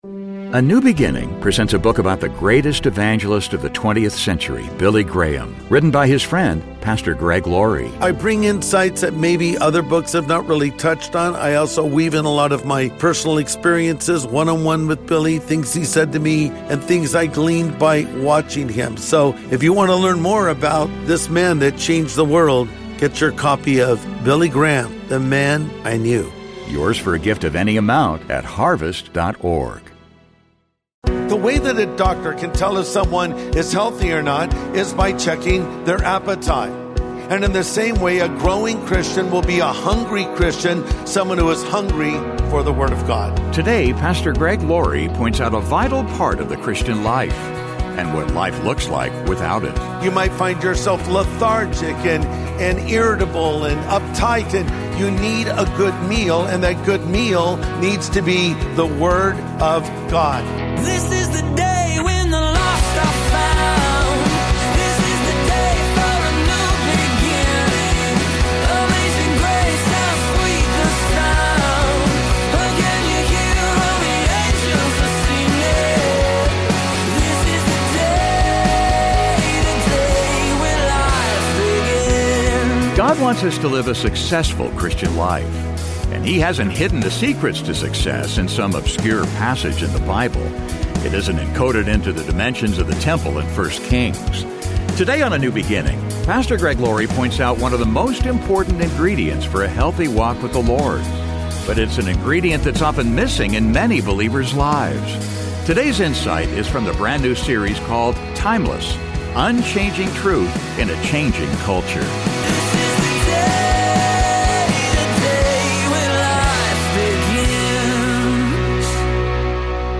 Today on A NEW BEGINNING, Pastor Greg Laurie points out one of the most important ingredients for a healthy walk with the Lord.